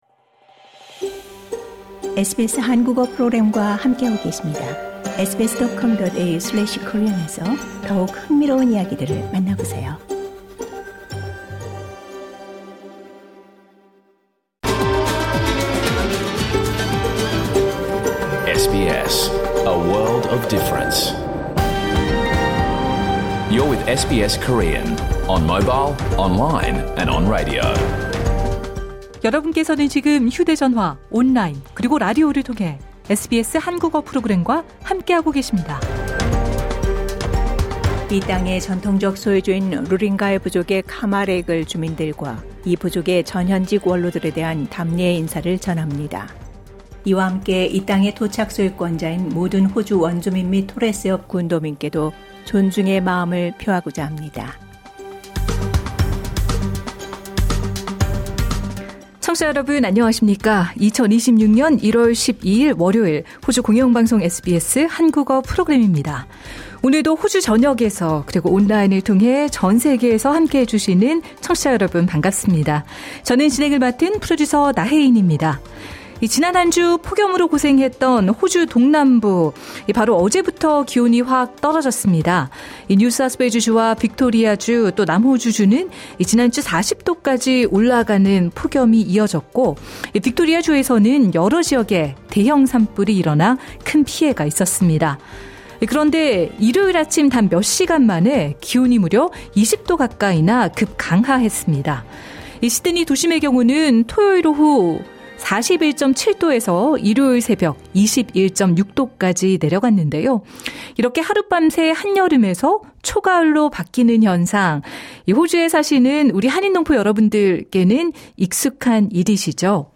2026년 1월 12일 월요일에 방송된 SBS 한국어 프로그램 전체를 들으실 수 있습니다.